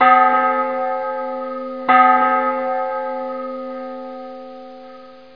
1 channel
bell2.mp3